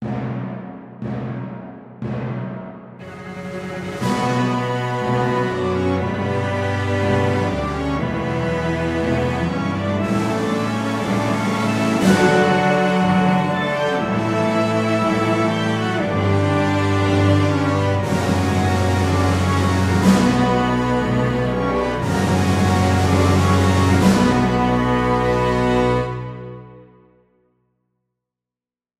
Seven varations for orchestra